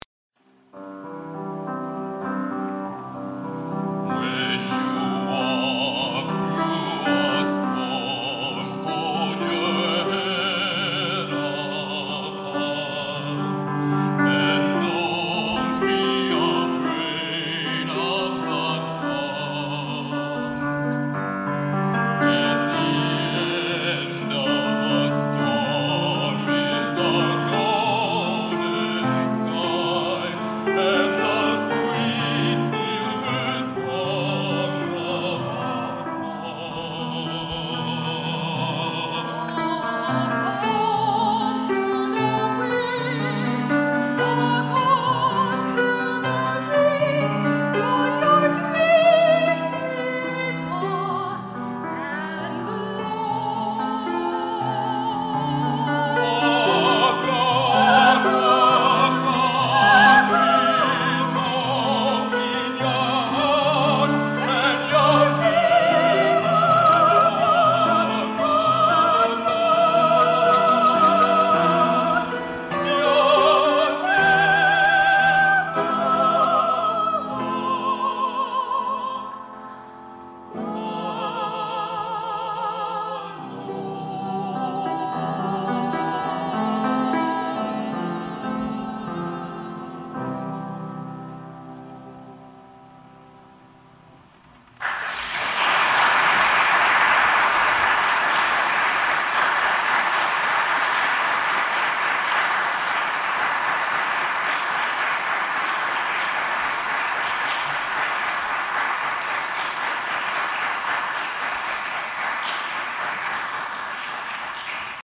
A duet recorded over 37 years ago